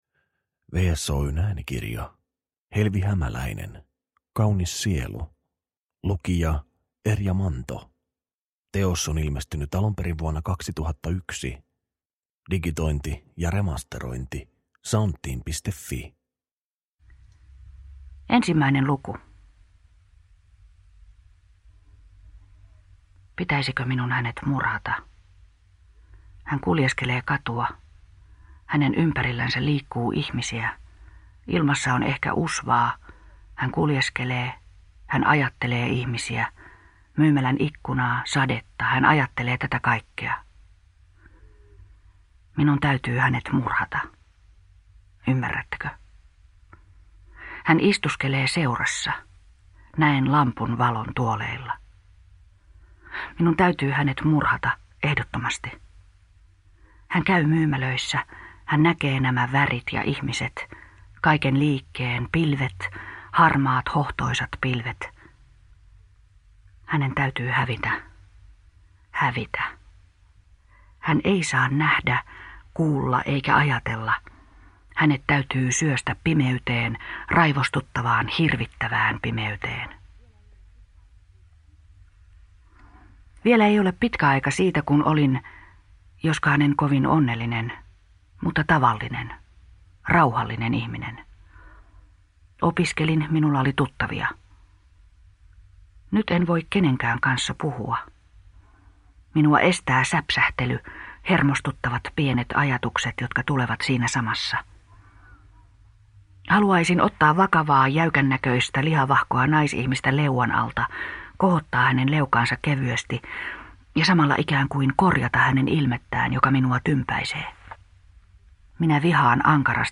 Kaunis sielu – Ljudbok – Laddas ner